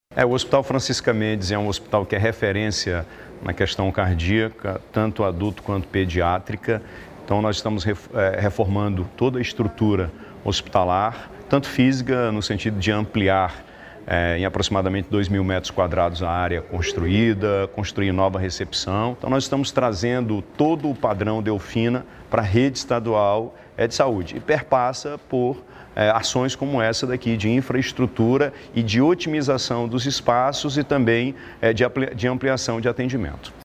O Hospital do Coração Francisca Mendes, localizado na zona norte de Manaus, passará por uma significativa ampliação de sua capacidade de atendimento, conforme destacou o governador Wilson Lima durante a vistoria das obras de reforma na unidade.
SONORA01_WILSOM-LIMA.mp3